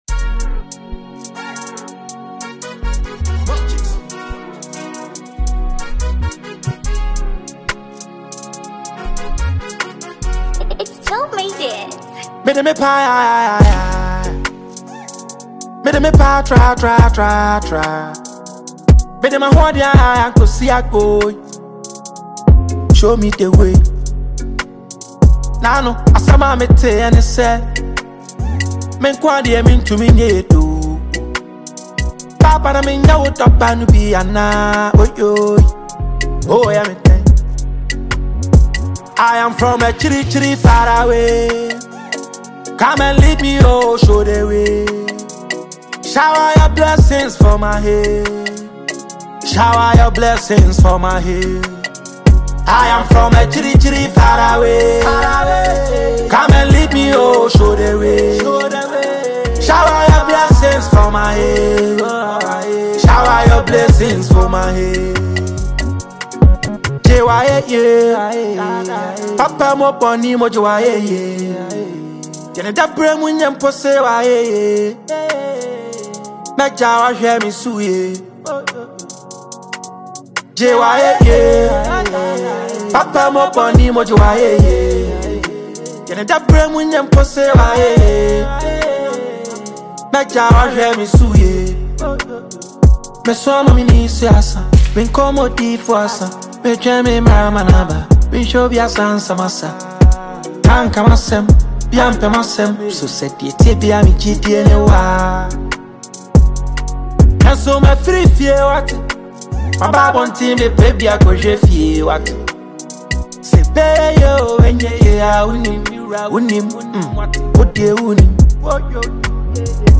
Award-winning Ghanaian rapper and songwriter